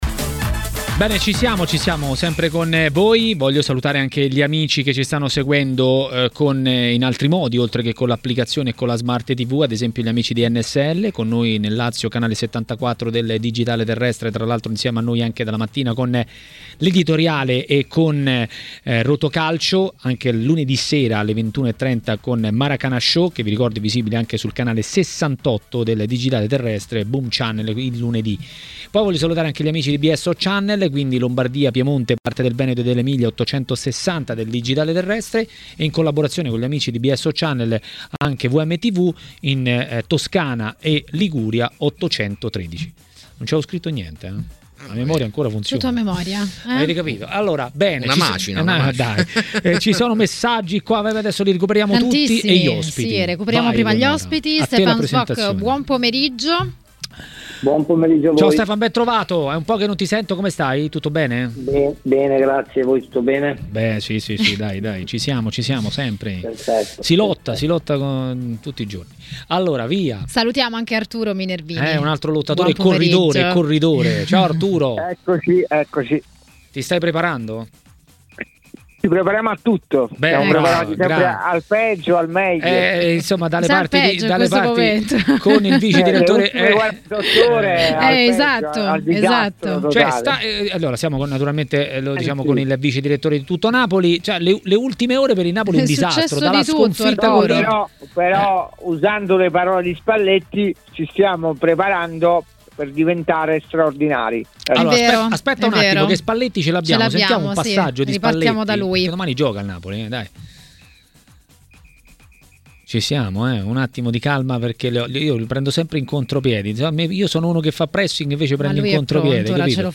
L'ex calciatore Stefan Schwoch a TMW Radio, durante Maracanà, ha parlato del momento del Napoli.